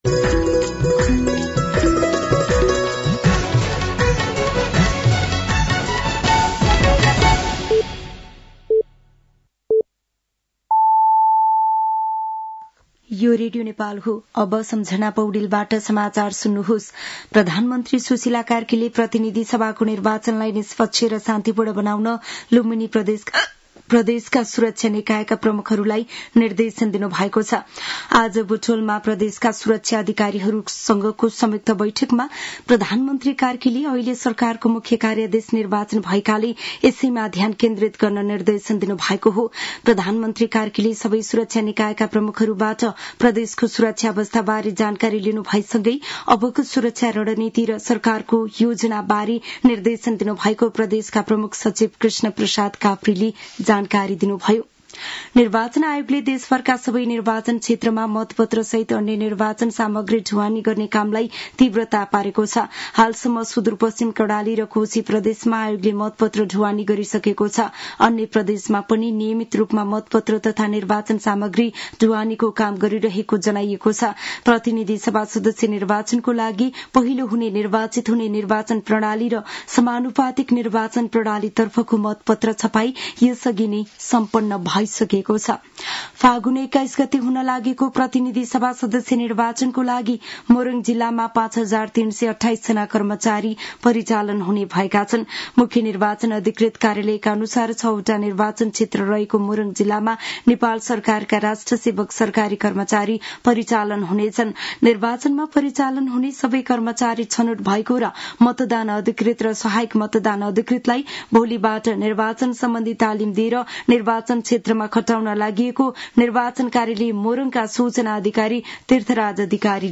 साँझ ५ बजेको नेपाली समाचार : ९ फागुन , २०८२